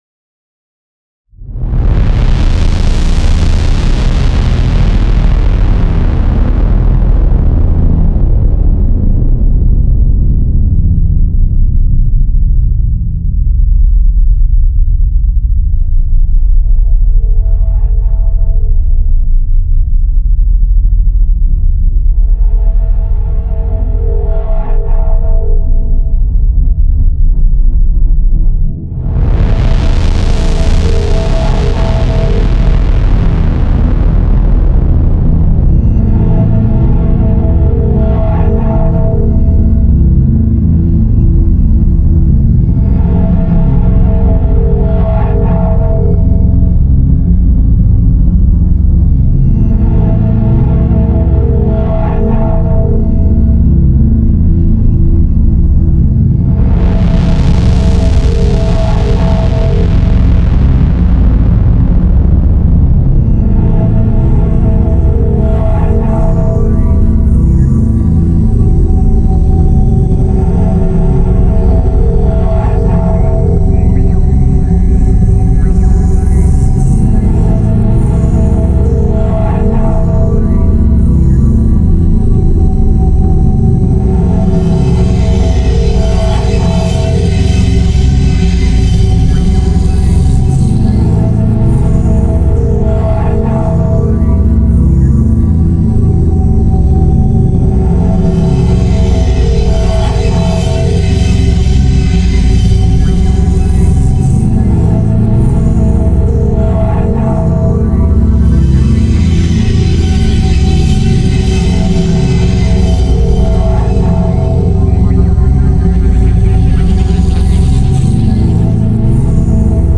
Part 1 is the ambient side
dub-inspired idm
ambient pads and drones